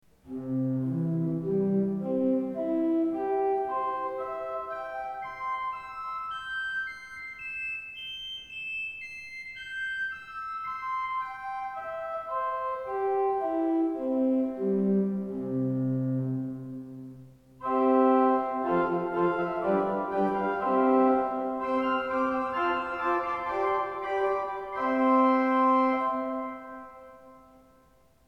Der Klang der Principalregister ist kräftig-rund, und sie zählen neben den Flötenregistern zu den wichtigsten Registern einer Orgel.
Diese Klangbeispiele sind zwar alle improvisiert, aber ich habe versucht, sie möglichst ähnlich zu spielen.